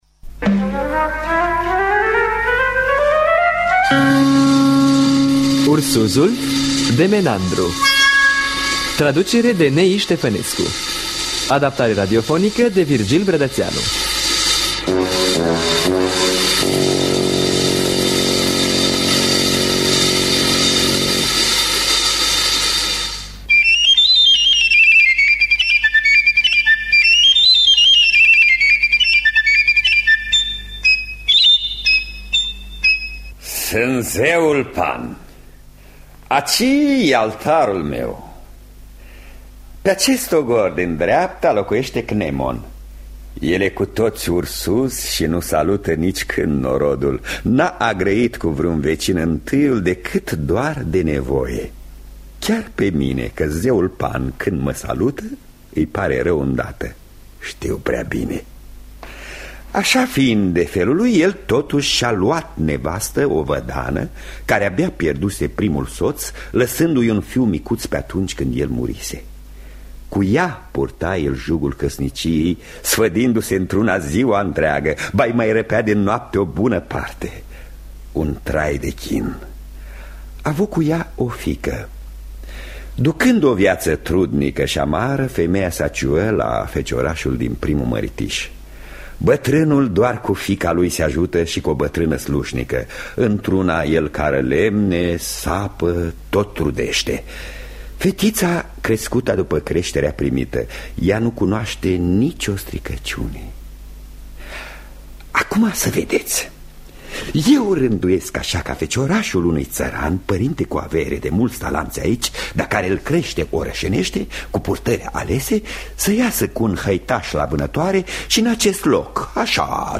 Ursuzul de Menandru – Teatru Radiofonic Online